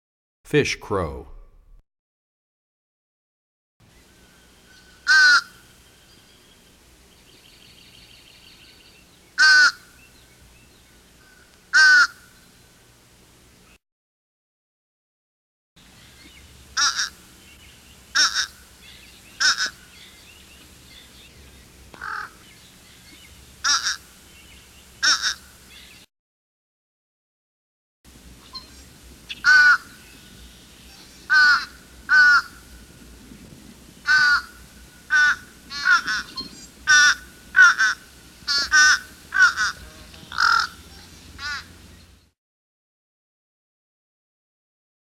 36 Fish Crow.mp3